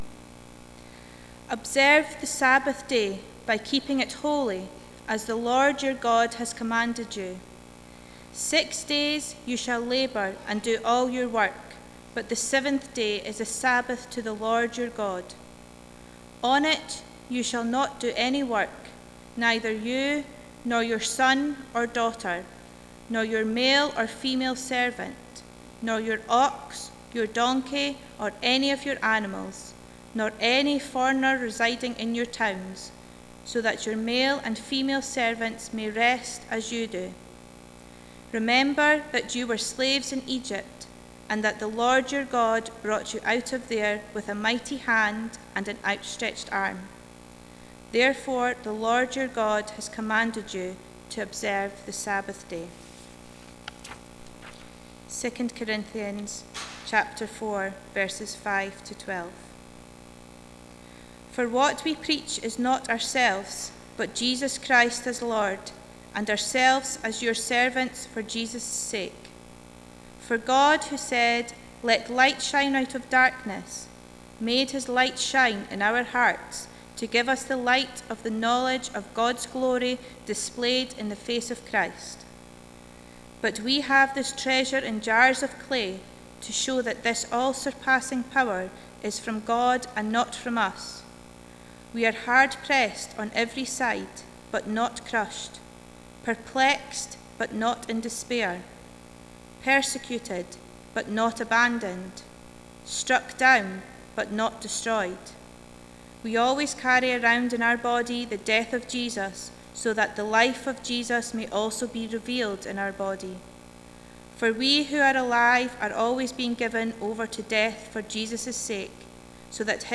Passage: Deuteronomy 5:12-15, 2 Corinthians 4:5-12, Mark 2:18 -3:12 Service Type: Sunday Morning « Jesus